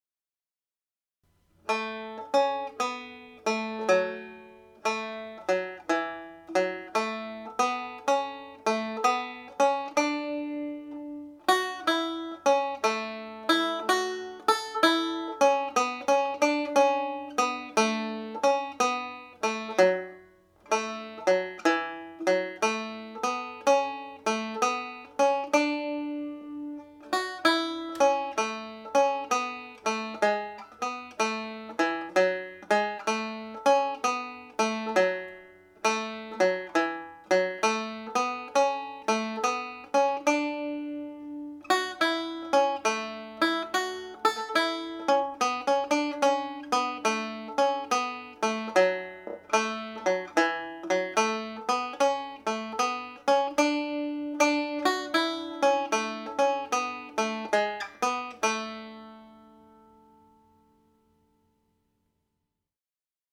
Frank’s reel has been a very popular up lifting tune in many sessions I have played in.
part one played slowly